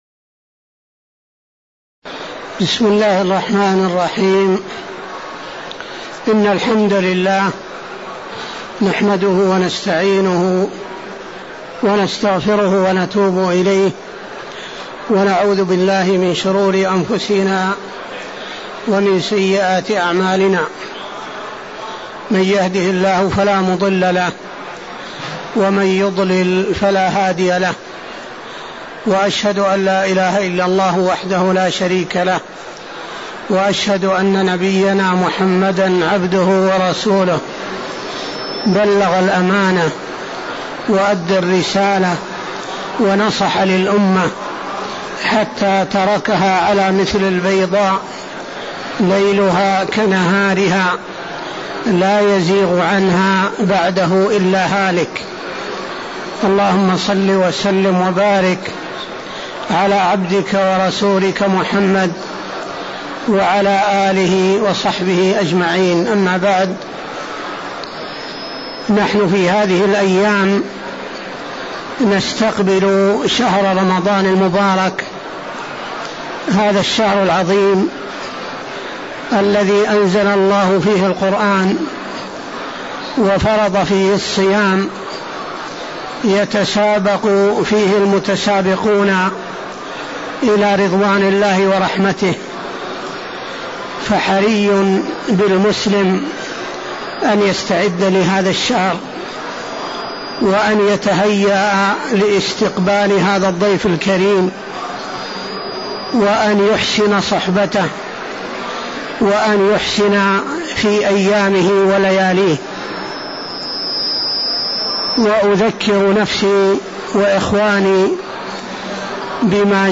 من دروس ومحاضرات الشيخ في المسجد النبوي